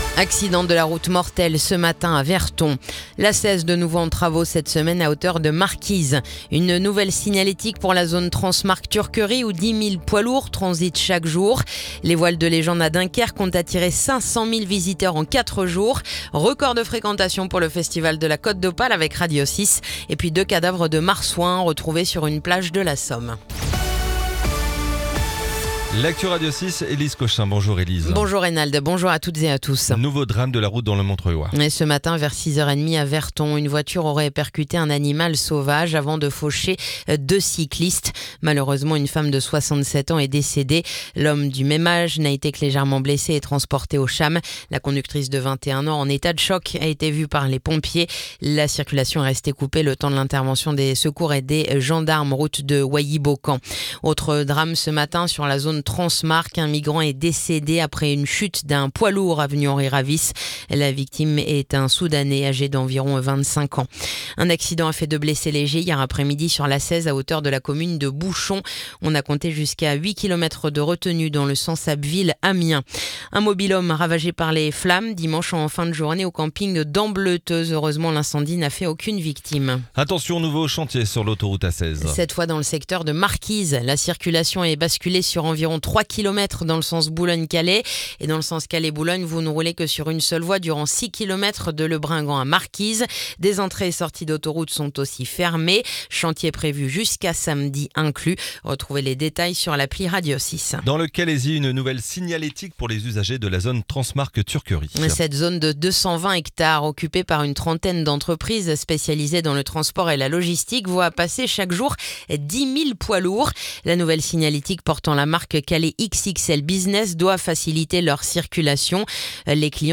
Le journal du mardi 15 juillet